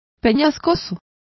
Complete with pronunciation of the translation of craggier.